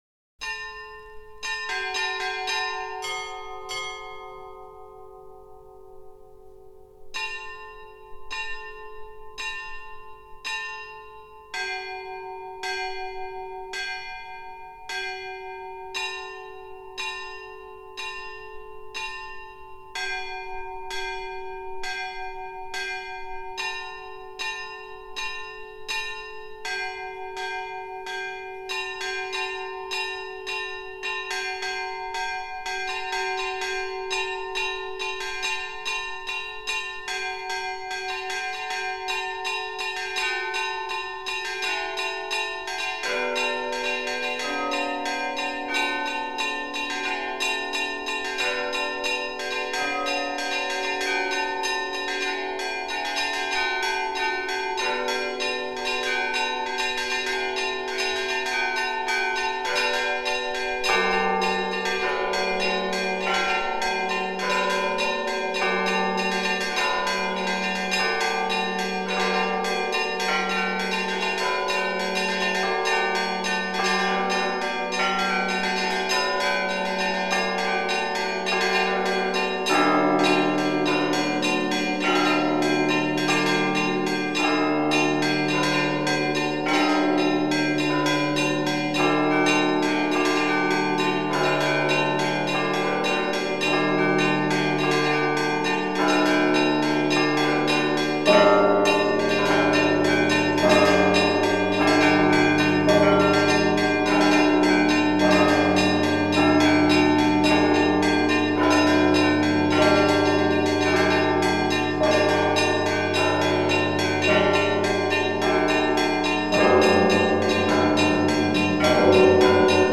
Index of /lib/fonoteka/etnic/rossia/kolokola/rostov
11_Svadebnyj_Ili_Razgonnyj_Zvon.mp3